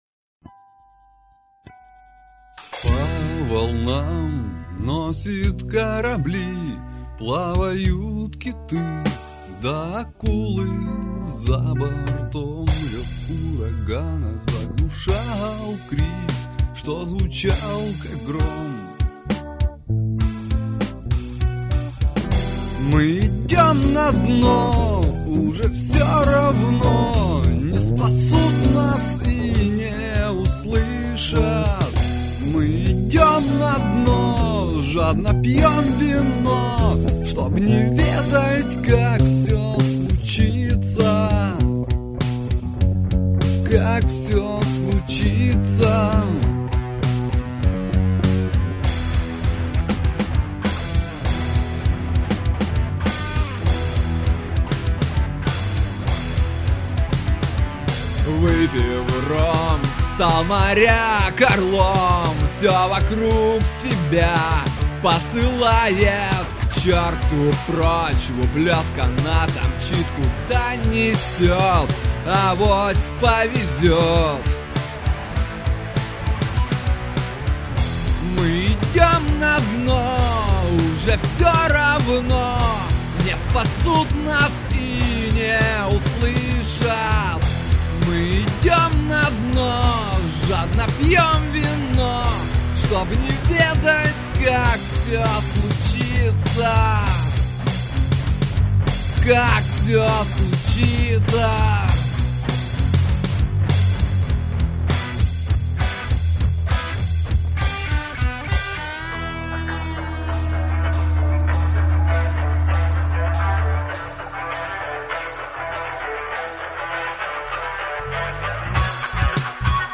*Рок - Музыка